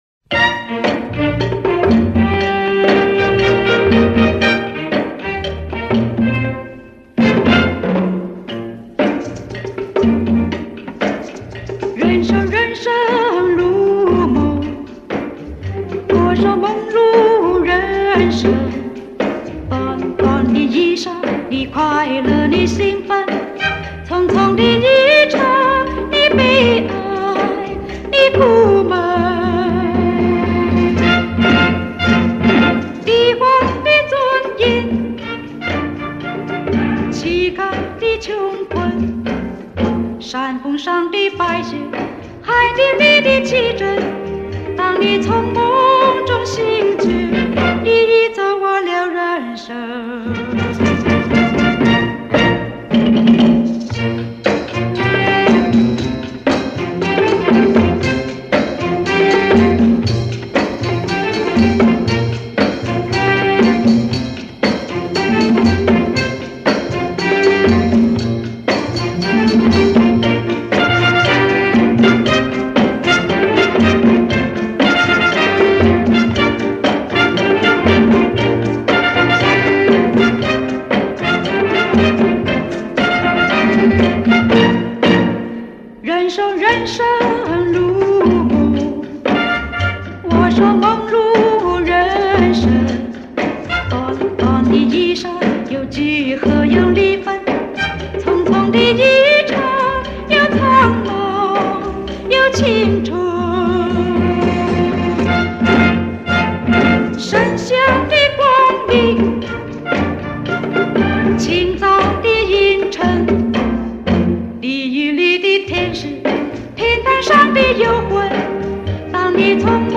由于是年代久远的录音，希望激励网友不要介怀音质不好。